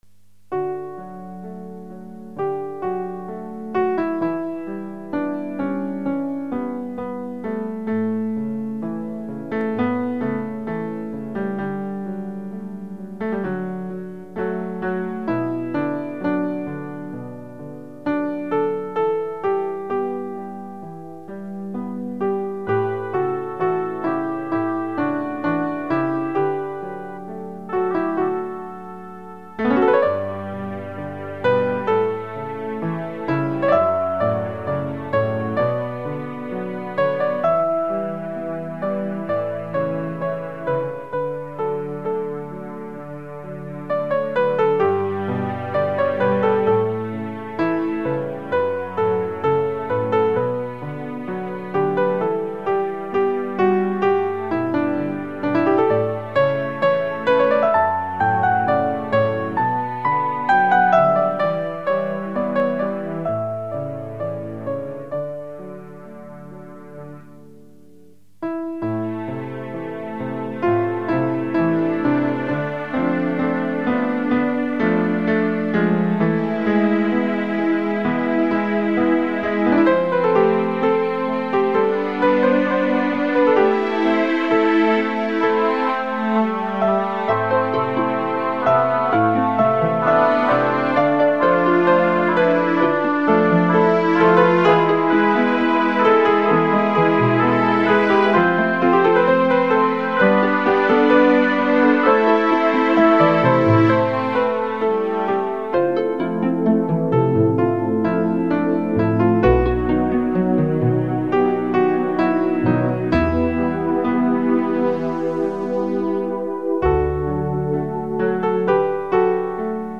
Lugn pianomusik.